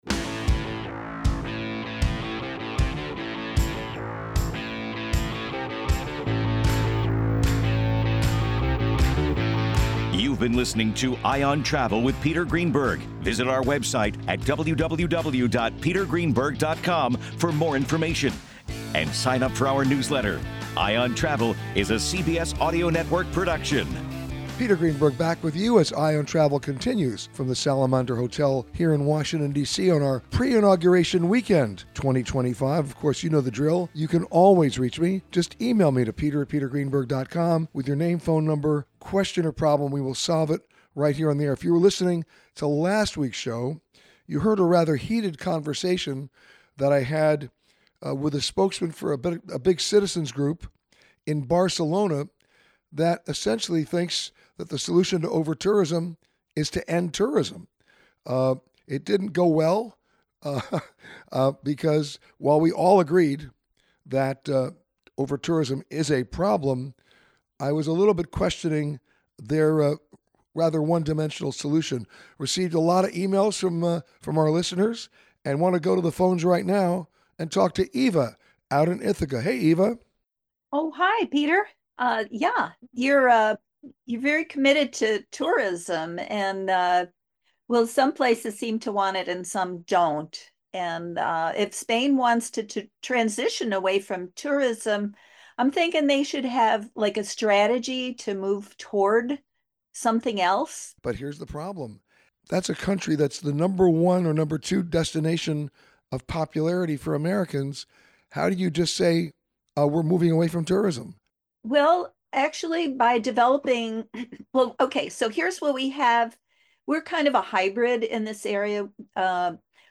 This week, Peter answered your questions from the Salamander hotel in Washington DC. Peter answers your questions on travel insurance, overtourism, the FAA, and more. Each week during our CBS Radio Show, Eye on Travel, Peter answers your travel questions.